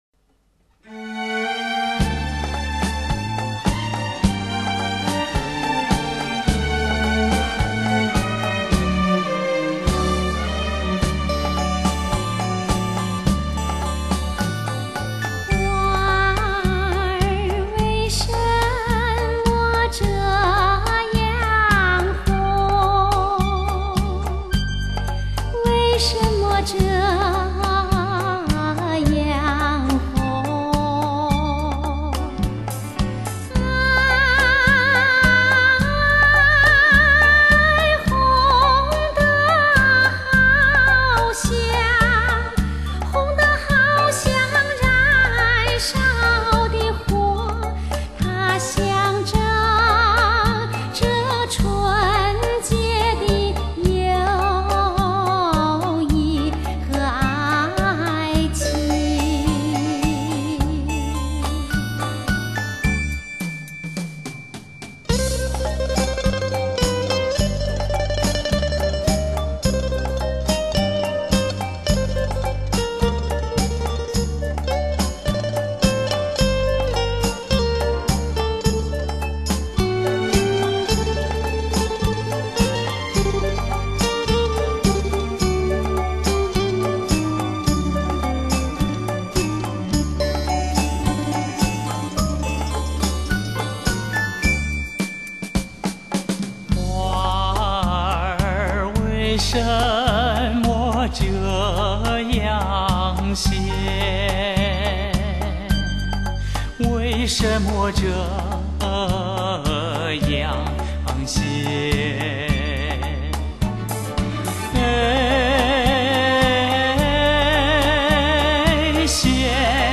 一曲曲的舞乐翩然而至，唤起了美好回忆，更给浪漫温馨披上了轻纱；灯光、旋律带带动你轻盈舞步，让你翩翩起舞于舞池中。